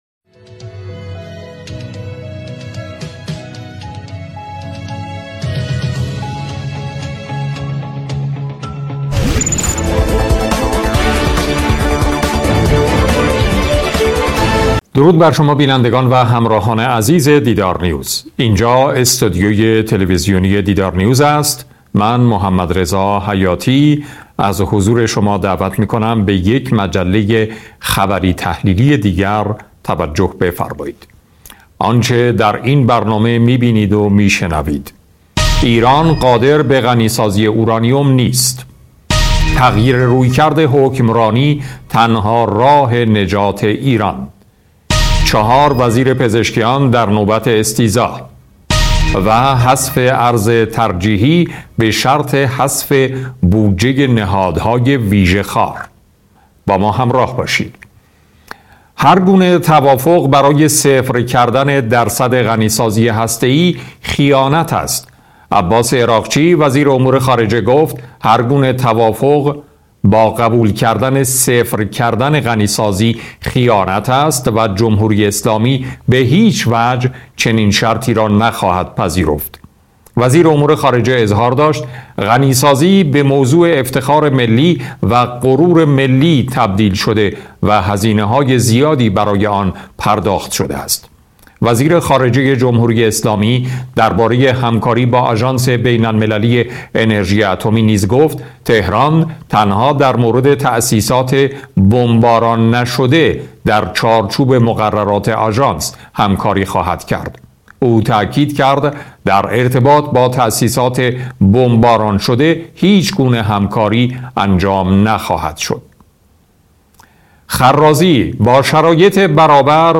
مجله خبری تحلیلی دیدارنیوز